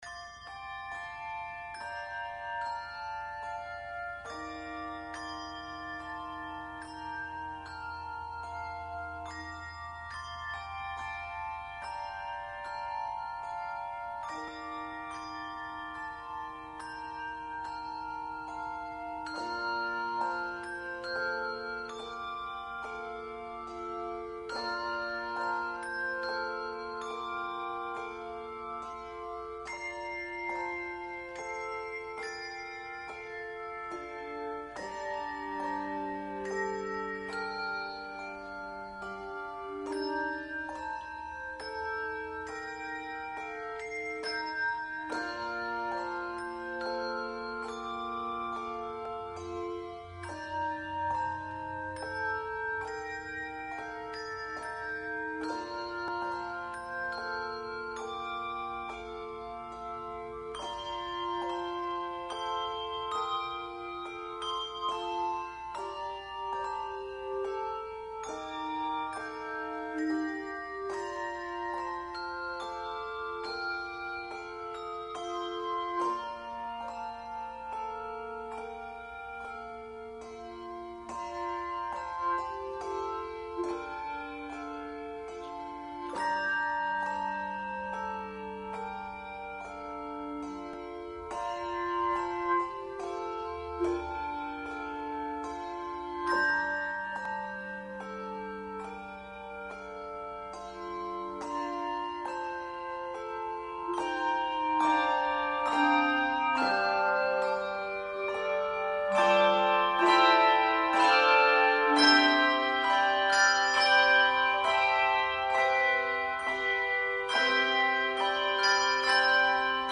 Handbell Quartet
Handbell Music
Genre Sacred
No. Octaves 4 Octaves